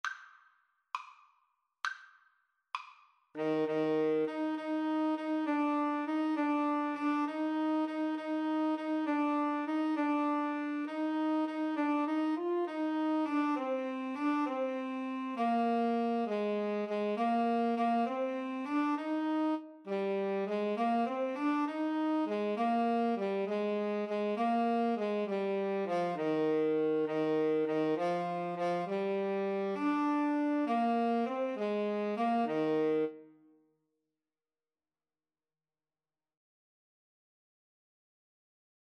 Alto SaxophoneTenor Saxophone
6/8 (View more 6/8 Music)
Classical (View more Classical Alto-Tenor-Sax Duet Music)